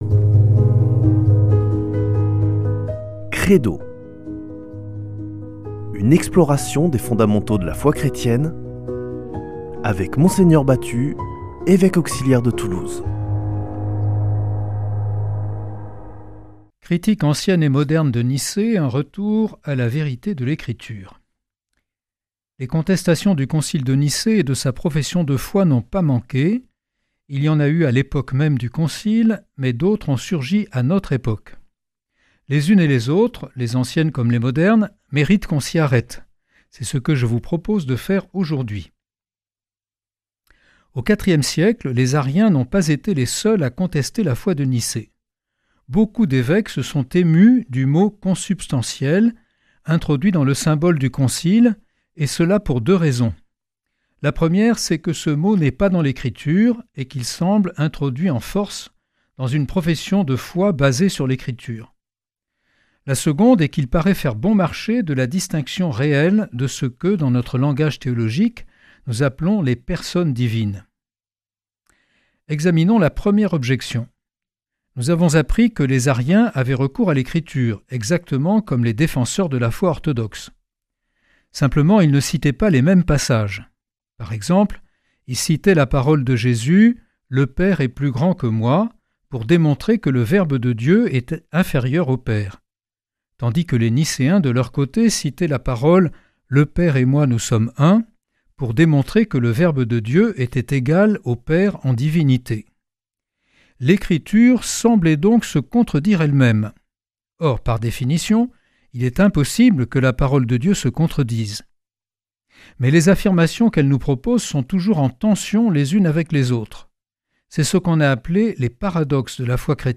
Une émission présentée par
Evêque auxiliaire de Toulouse